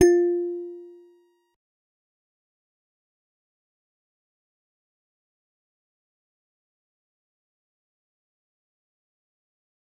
G_Musicbox-F4-mf.wav